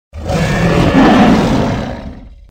dragontiger_draw_shout.mp3